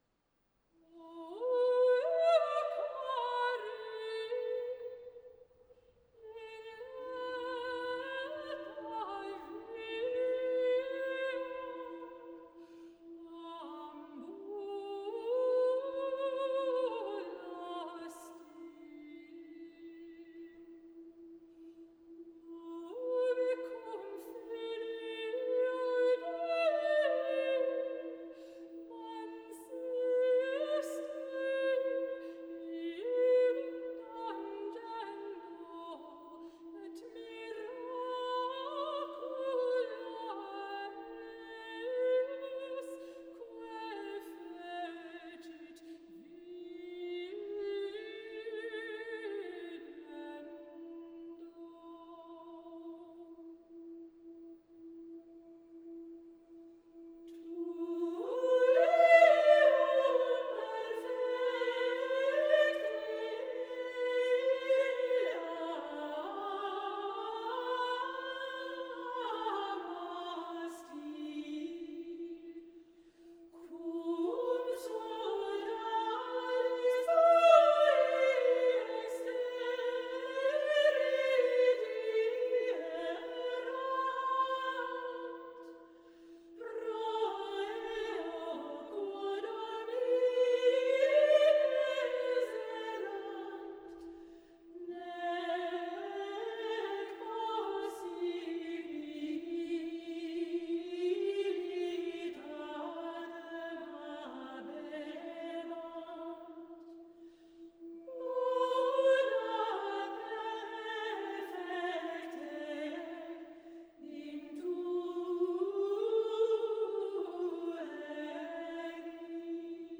Period: Medieval
Genre: Plainsong
• The shape of the solo voice at the beginning. It soars upwards in leaps before moving in a step wise motion.
• When the other voices in, they create a sense of timelessness – a calm, devotional, meditative atmosphere by holding a single note called a drone underneath the solo voice.
• Listen to the effect of the two parts together - the one moving freely, the other long and sustained on the same pitch.
• The pattern of musical notes used (Phrygian mode) returns again and again to the note E, creating a distinctive chant-like sound.
Listen to the BBC Singers perform an extract of Hildegard of Bingen's O Euchari (mp3)